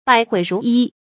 百喙如一 bǎi huì rú yī
百喙如一发音
成语注音ㄅㄞˇ ㄏㄨㄟˋ ㄖㄨˊ ㄧ